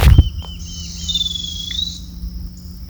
Anambé Castaño (Pachyramphus castaneus)
Nombre en inglés: Chestnut-crowned Becard
Localización detallada: Misión de Loreto
Condición: Silvestre
Certeza: Fotografiada, Vocalización Grabada
Anambe-castano_1.mp3